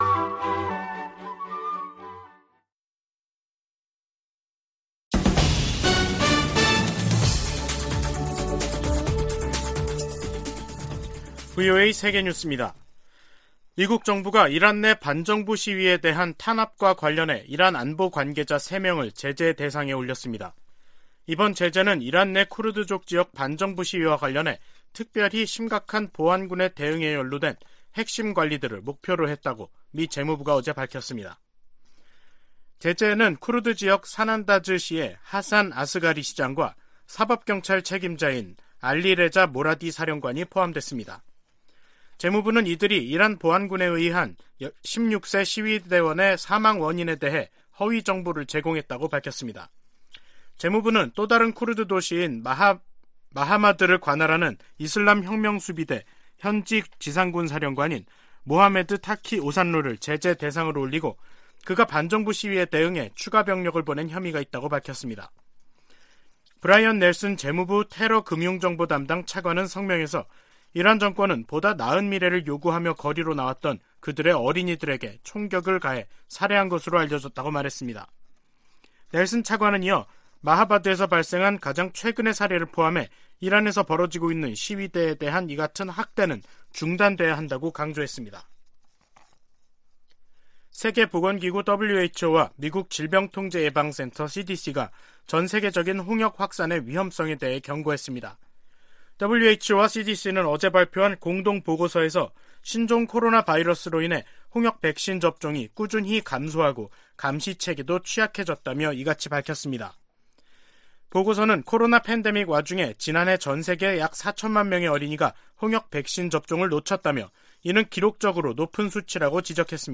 VOA 한국어 간판 뉴스 프로그램 '뉴스 투데이', 2022년 11월 24일 2부 방송입니다. 미 국무부는 미한 연합훈련에 대한 북한의 비난을 일축하며 북한이 방어적인 훈련을 구실로 불법 행동을 이어가고 있다고 지적했습니다. 한국 정부는 북한 김여정 노동당 부부장의 대남 비난담화에 도를 넘었다며 강한 유감을 표명했습니다.